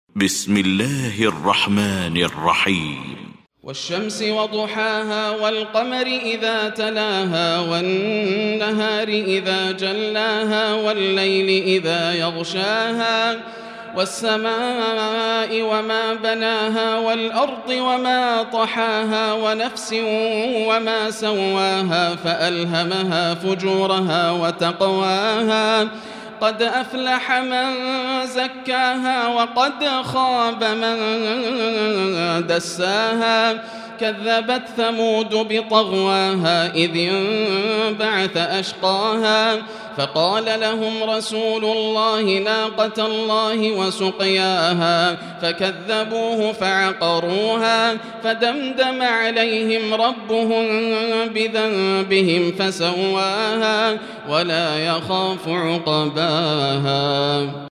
المكان: المسجد الحرام الشيخ: فضيلة الشيخ ياسر الدوسري فضيلة الشيخ ياسر الدوسري الشمس